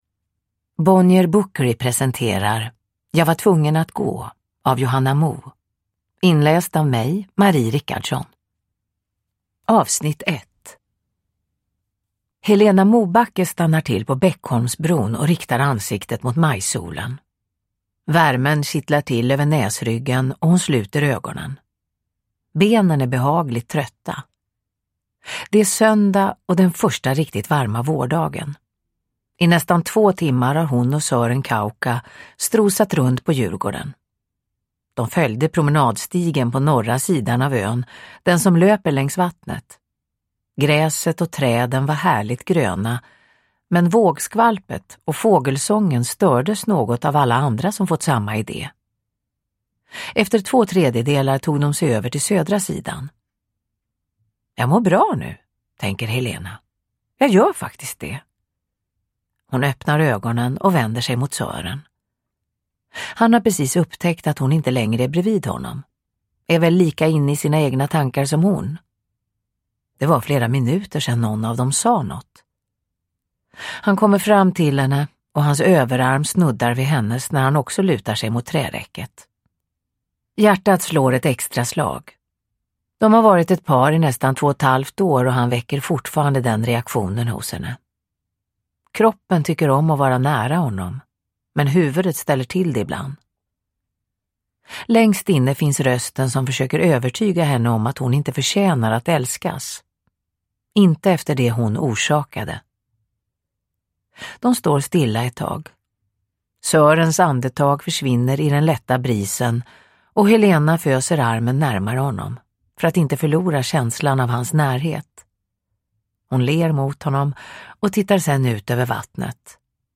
Uppläsare: Marie Richardson
Ljudbok